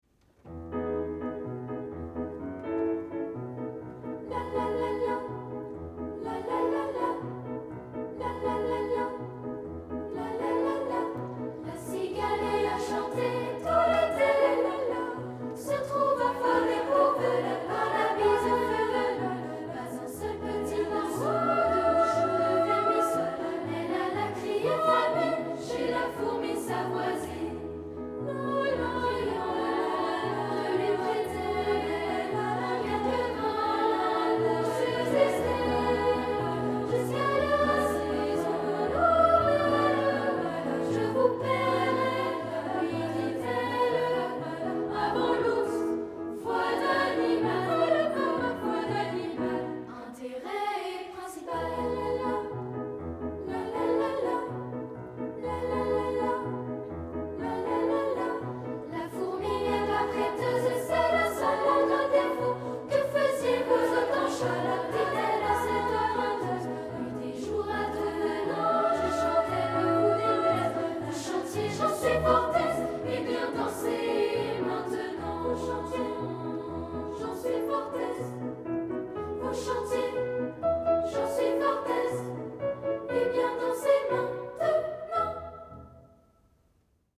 Genre :  ChansonComptine
Style :  Avec accompagnement
Effectif :  PolyphonieUnissonVoix égales
3 voix et piano (audio)